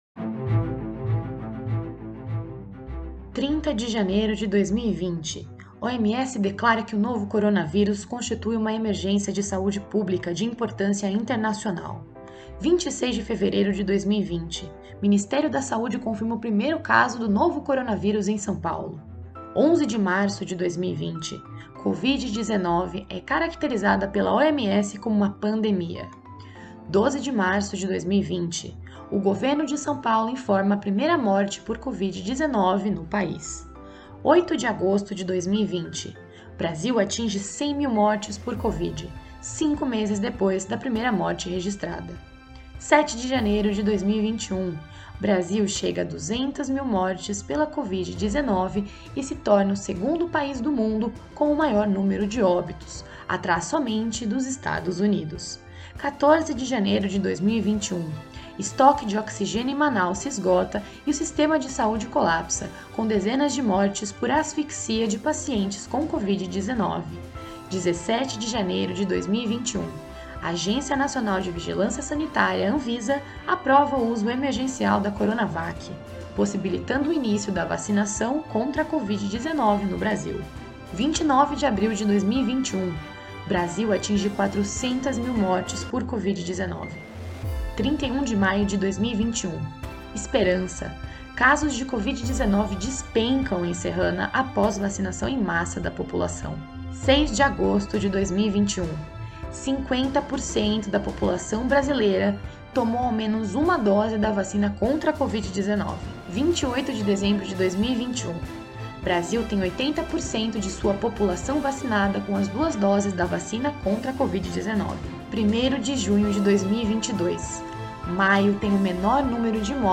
O jornalista comentou sobre os maiores desafios enfrentados nesses últimos dois anos, quais foram as adaptações dentro das redações, e contou sobre os momentos mais marcantes da cobertura dessa crise humanitária. O podcast é composto por entrevistas e notas opinativas.